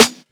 YUTE_SNR.wav